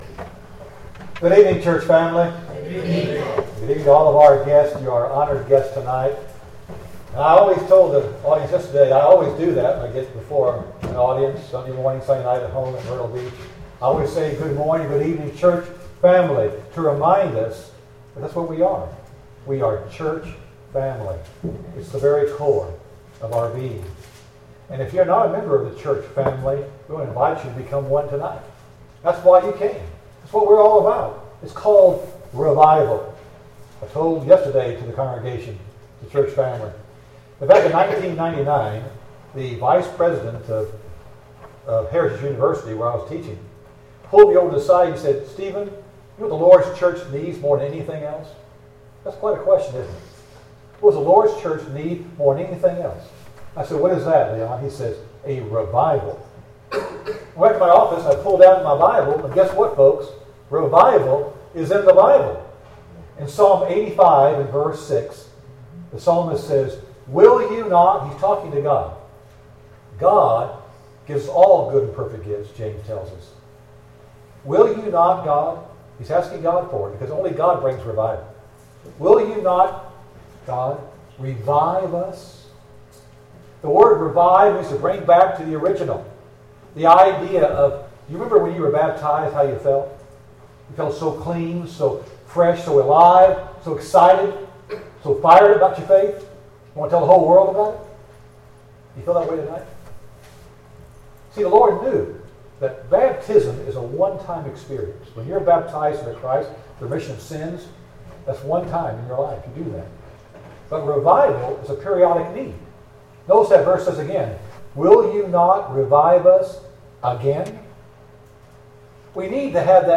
II Corinthians 7:14 Service Type: Gospel Meeting Download Files Notes Topics: Revival